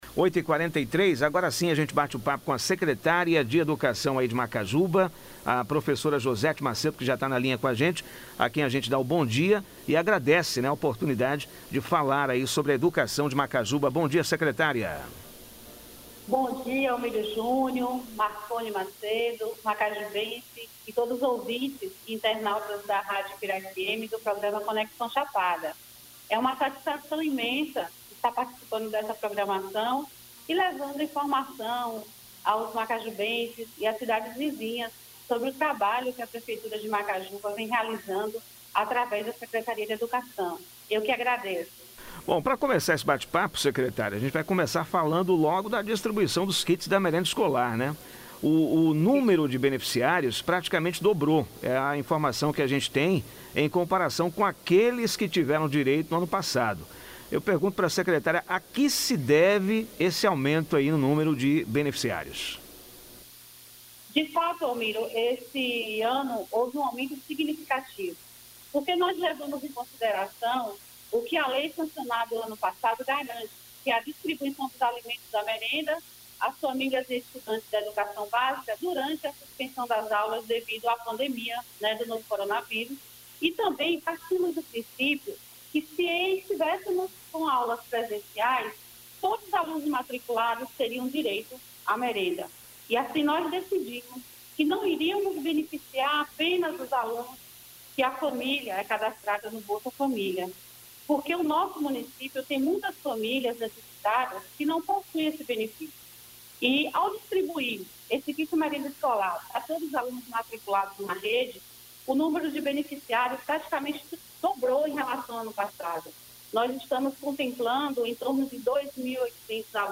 Entrevista com a Secretária de Educação Josete Macêdo.